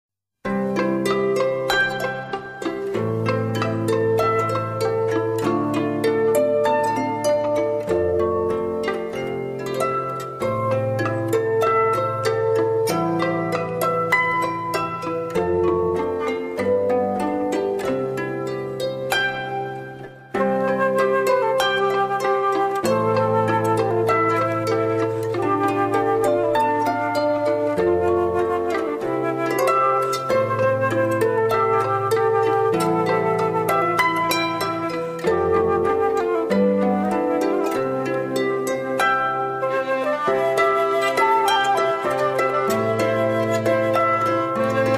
Folk / Celta/ World Music